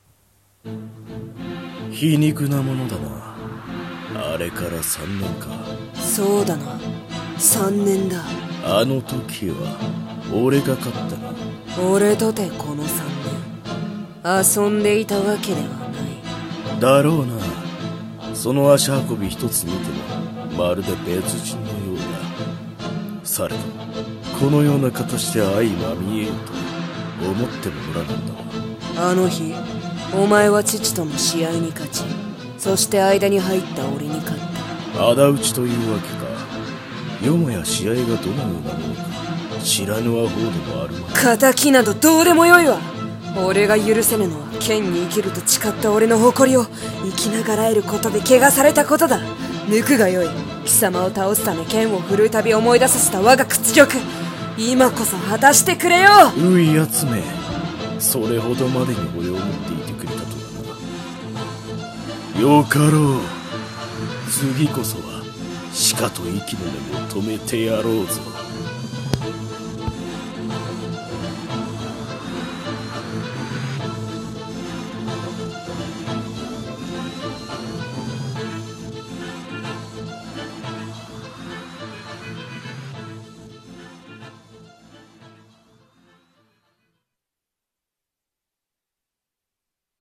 【戦闘風】二人の剣客【二人声劇】